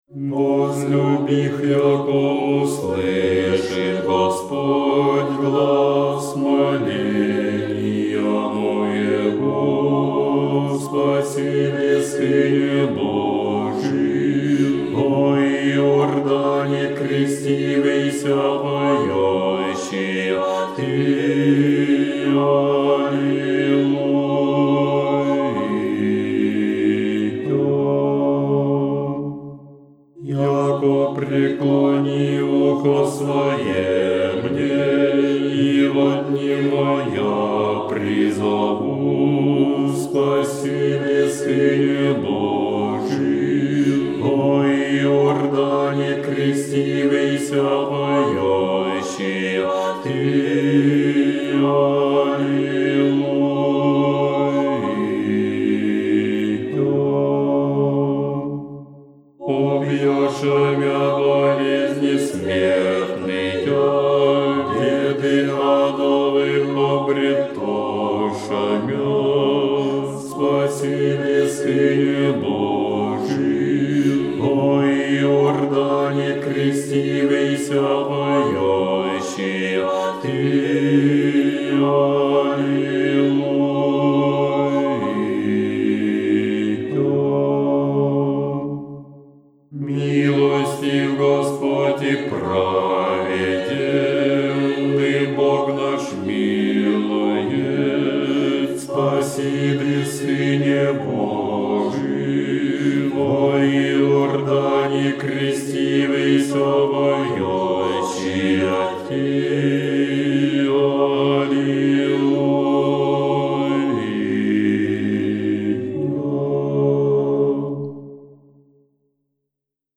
второй антифон
Bas-Kreschenie-Gospodne-Antifon-2muzofon.com_.mp3